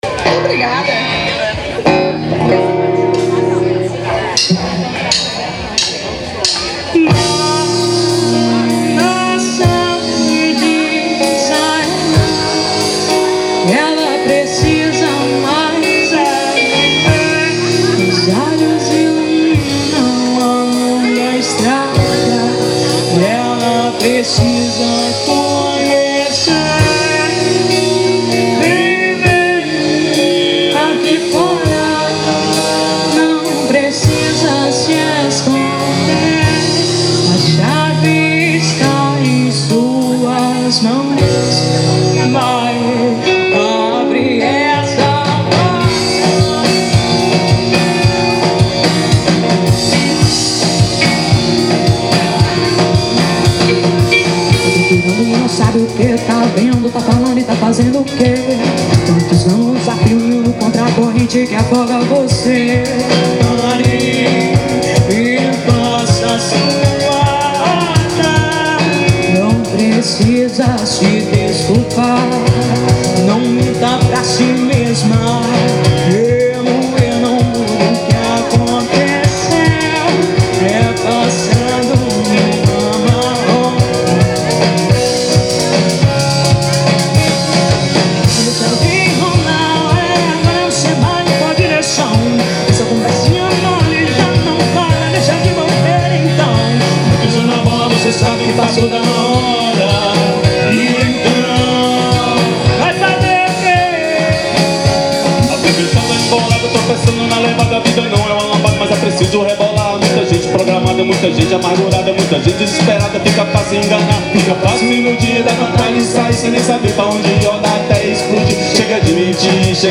EstiloBlack Music